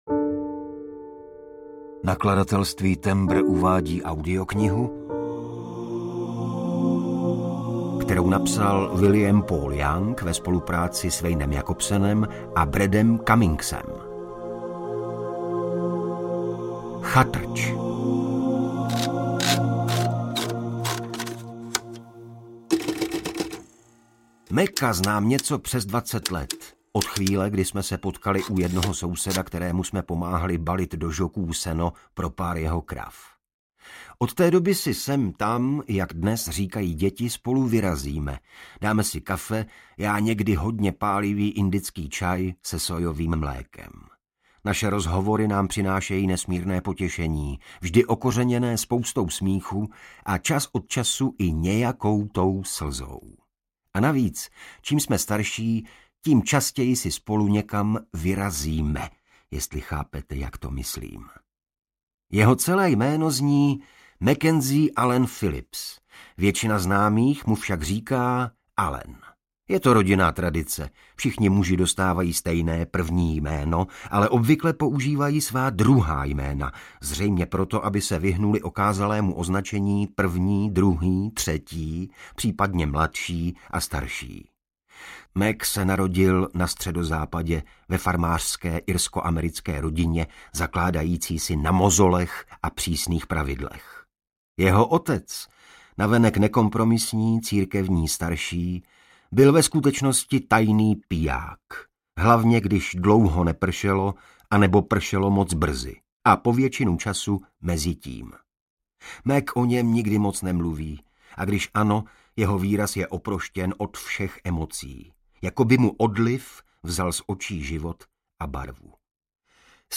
Chatrč – 2. vydání audiokniha
Ukázka z knihy
• InterpretIgor Bareš